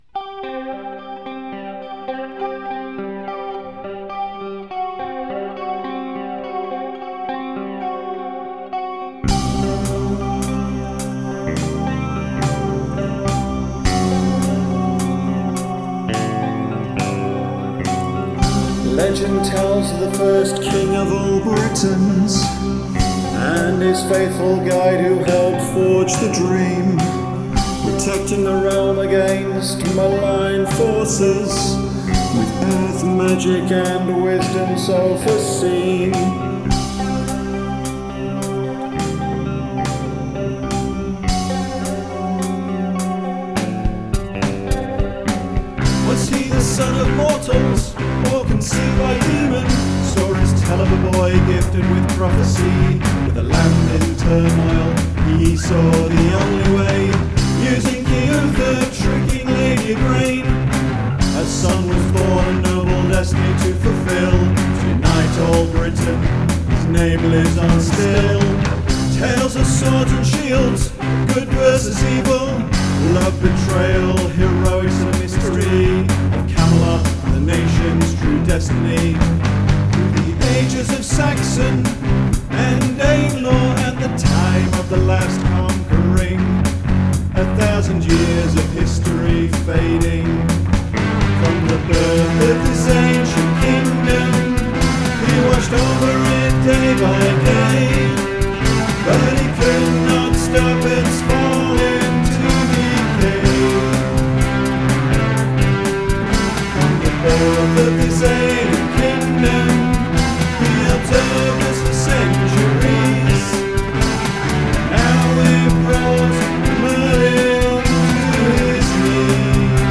Demo Recordings
This is a colection of self-recorded demo versions plus a live* track recorded direct to cassette tape, of the various songs I've written over the last 35+ years. The demos are all me and my trusty Zoom drum machine